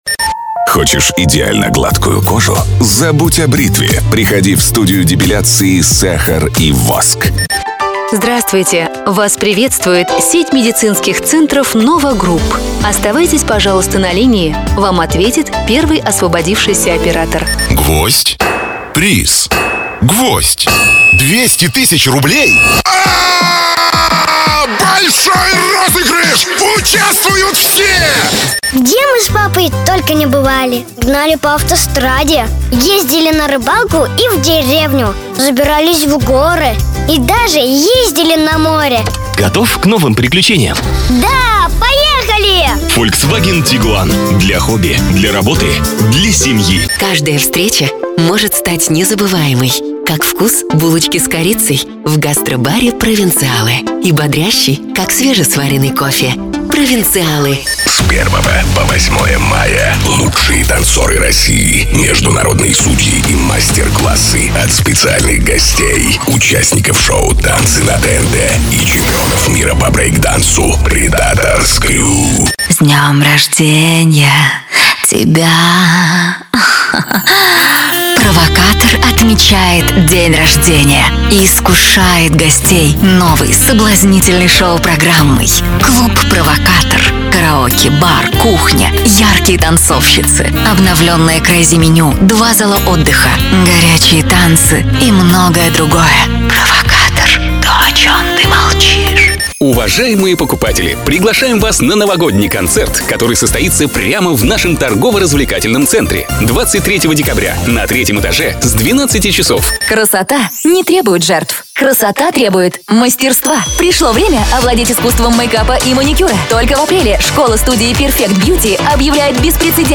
ДемоНарезкаАудиоРоликов.mp3